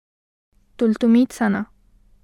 [ toltomiit sana ]